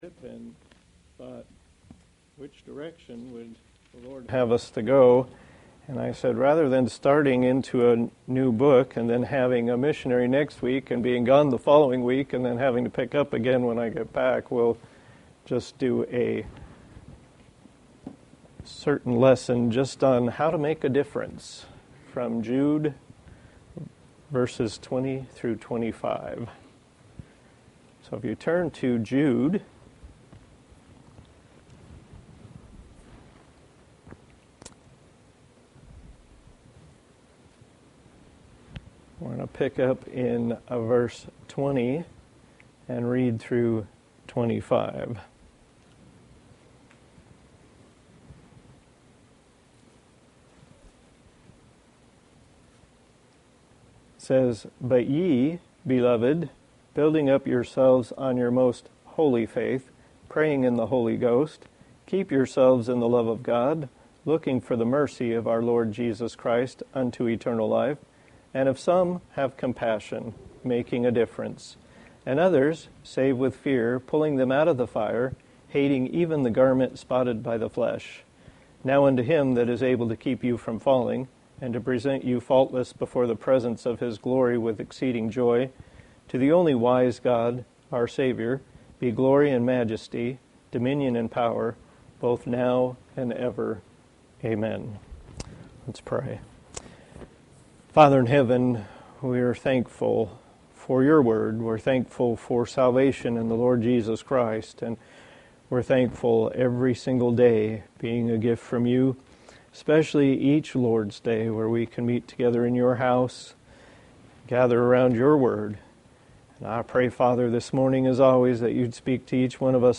Service Type: Sunday School